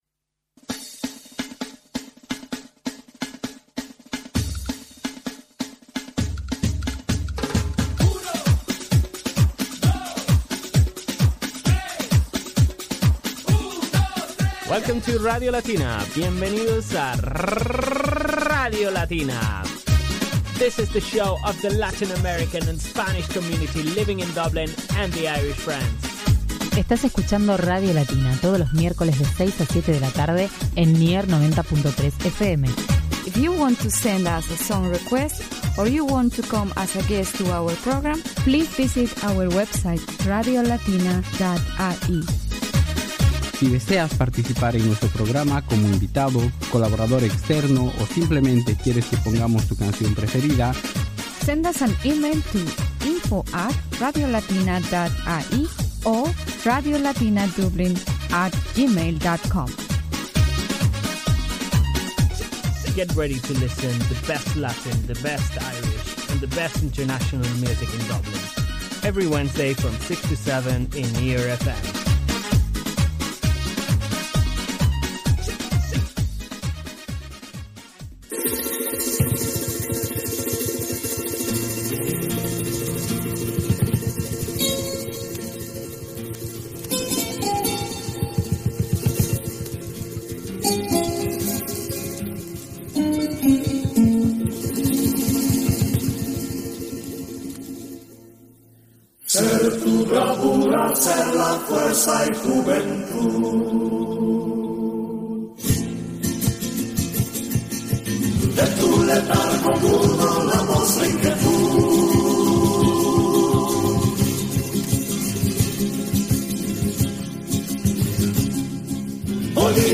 199 años de la independencia de Bolivia y una entrevista con Ecuatorianos en Irlanda